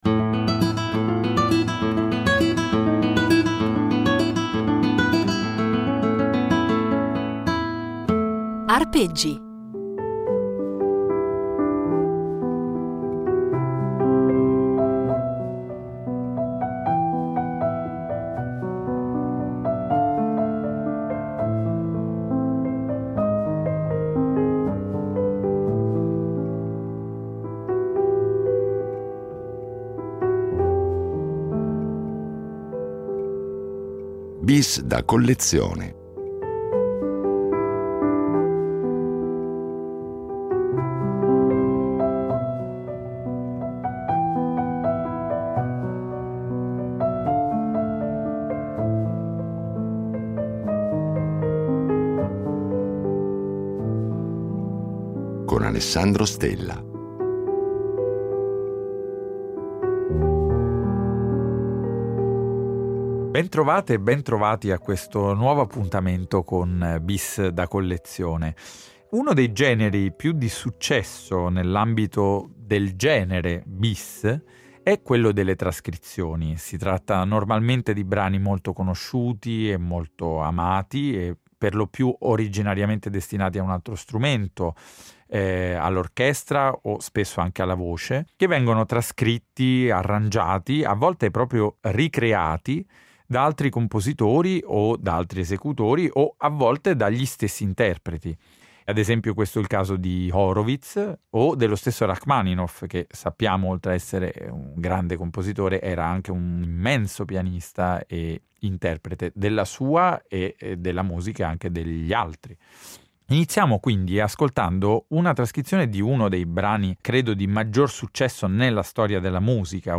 Lo fa dal suo personale punto di vista di interprete e prima ancora di ascoltatore, proponendoci alcuni dei brani per lui più significativi e, di conseguenza, alcuni dei pianisti che hanno segnato la sua crescita artistica.